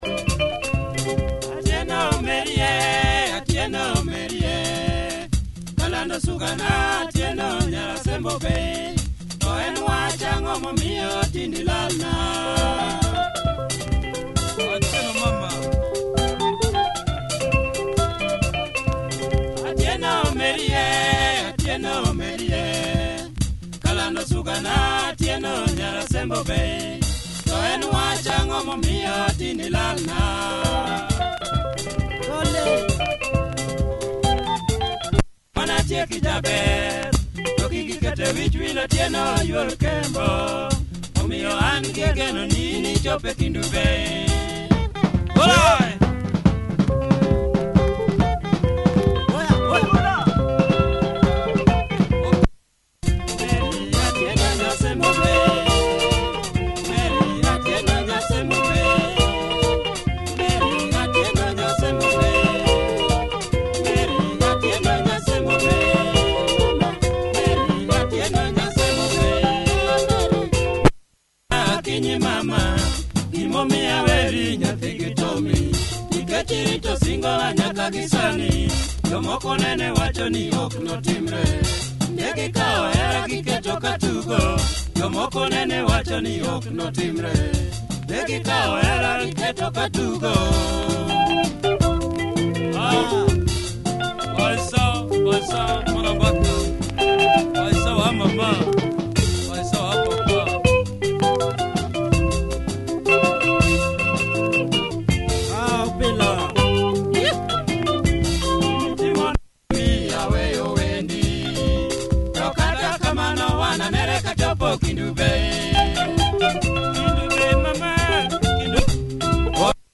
Nice luo benga